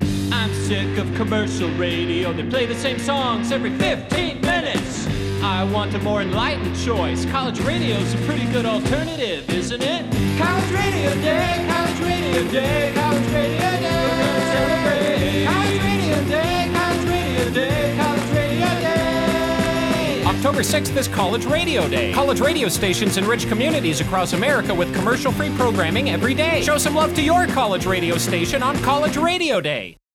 This is a promo for College Radio Day. I wrote and performed the original music here too.